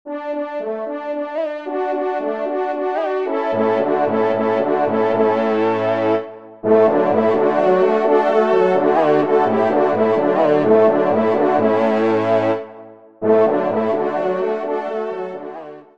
Genre : Musique Religieuse pour Quatre Trompes ou Cors
Pupitre 4° Trompe